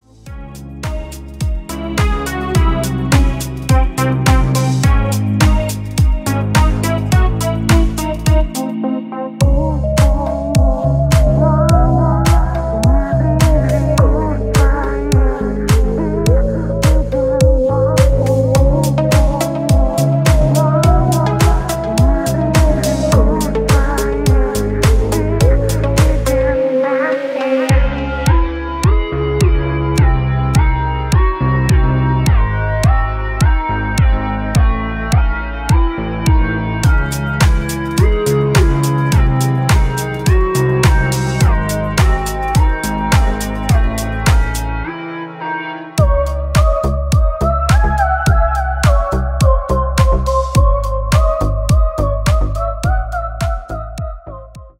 атмосферные
Electronic
EDM
Стиль: deep house.